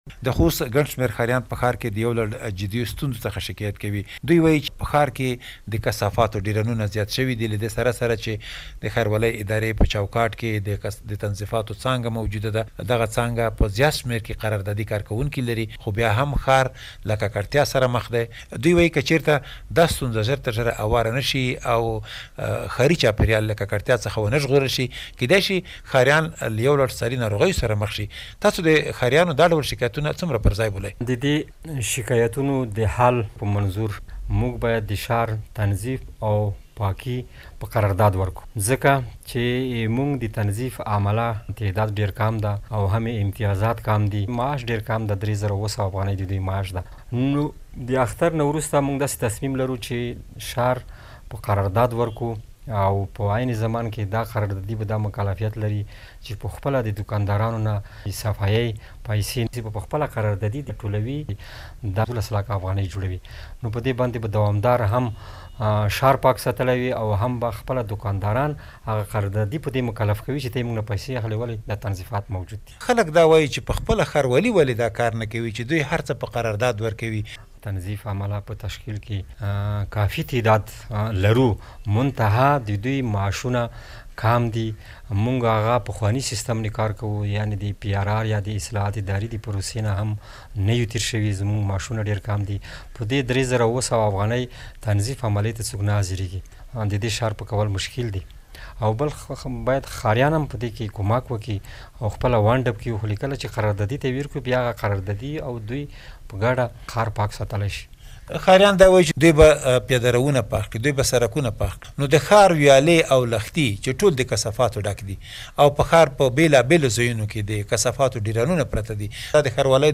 د خوست له ښاروال سره مرکه